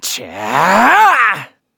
Kibera-Vox_Casting3_kr.wav